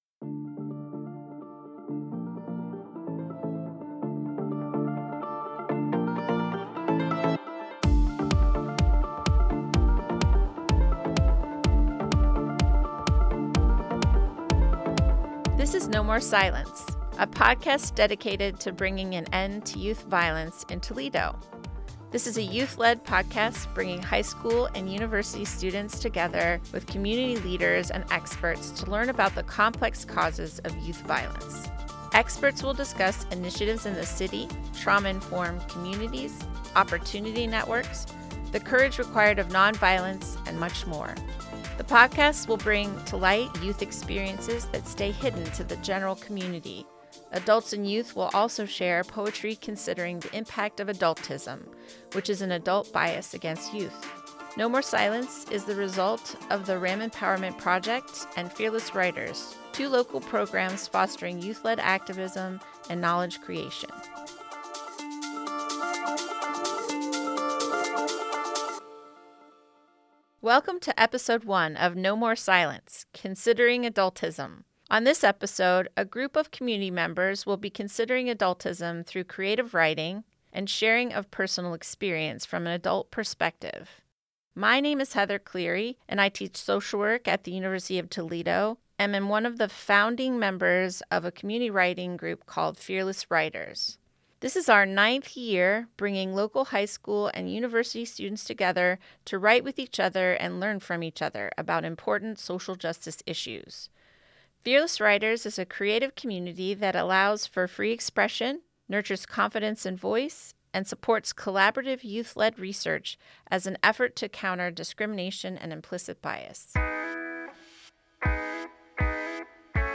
/*-->*/ /*-->*/ In the premiere episode of No More Silence, university students and adult members of the writing community will be sharing raw writing pieces they have written as part of a community empathy write that started in Nov 2024. The prompts for this writing project are songs picked by high school students as having a positive message and research information on adultism.